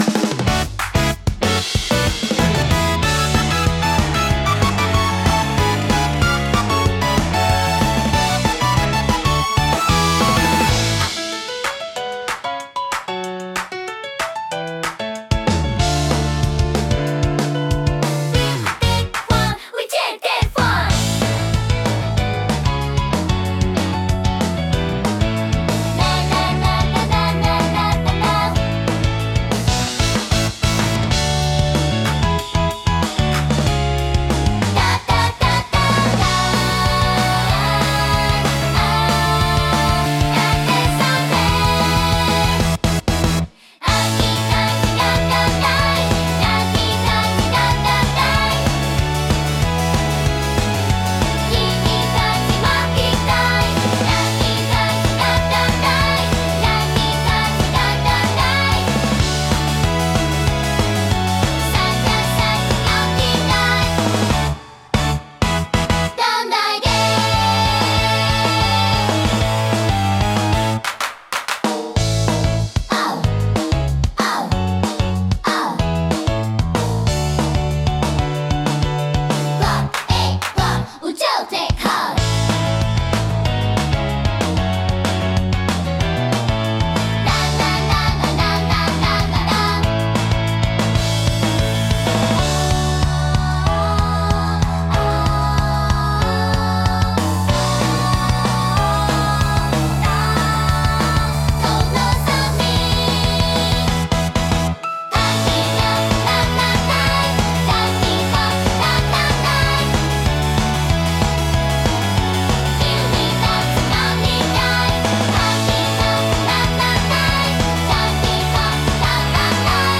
元気で前向きな印象を与え、若年層やファミリー向けコンテンツに特によく合います。